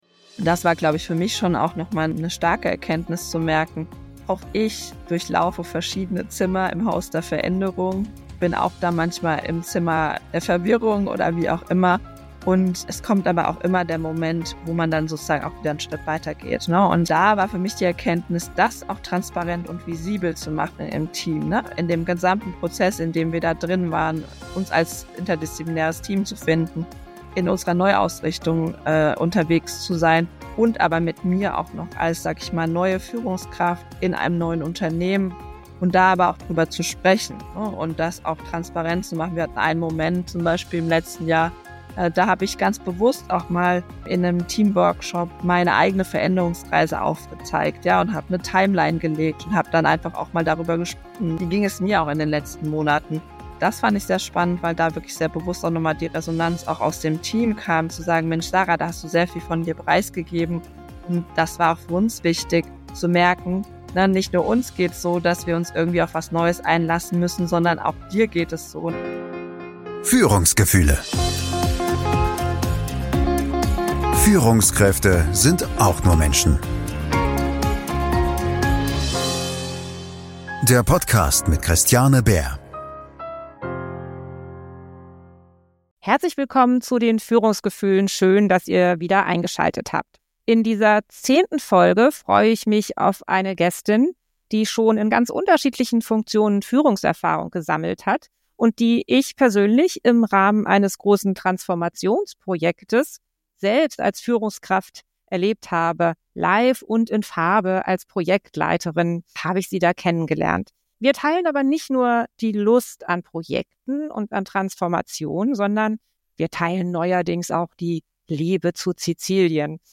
Emotionale Kurven - Führung in unwegsamem Gelände. Ein Gespräch